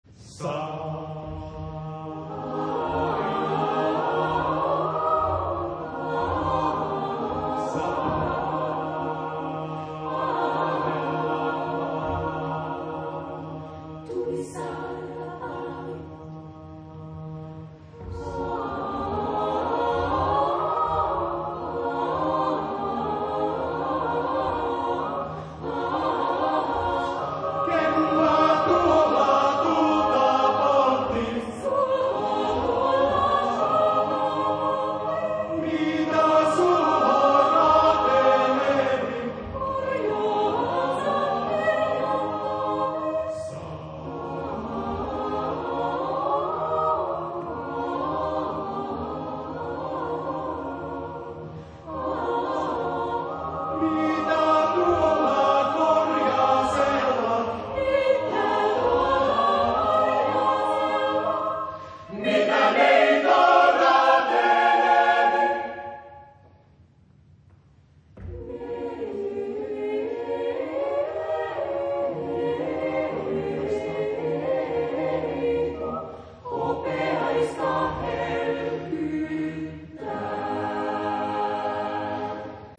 Genre-Style-Form: Secular ; Romantic Type of Choir: SATB (4 mixed voices )
Tonality: A flat major
Audio excerpt sung by Grex Musicus